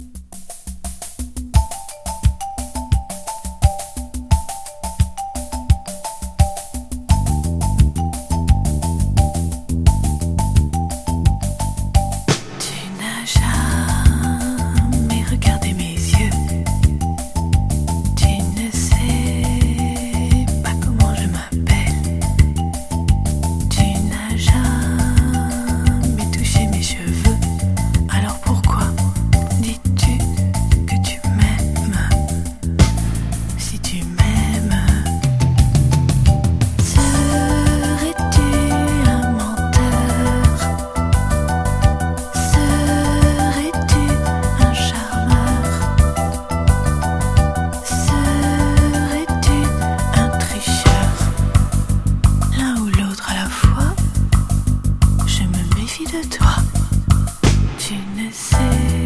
和レアリック